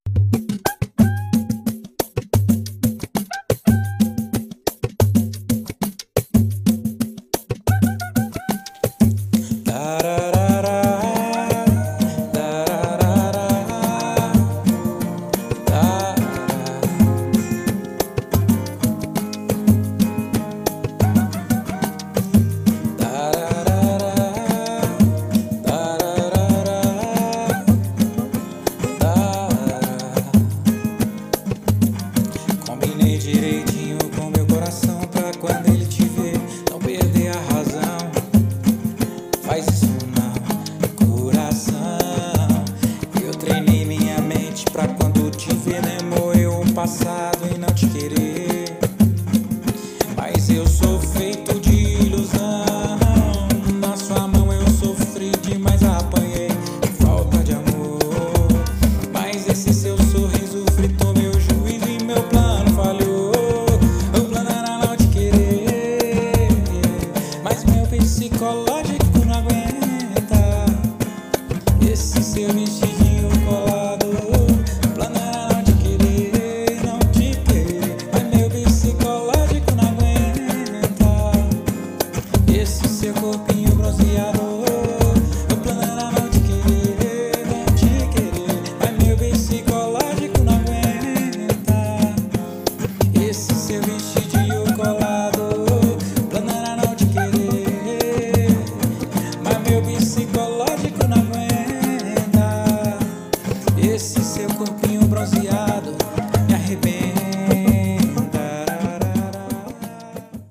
Sertanejo Botequeira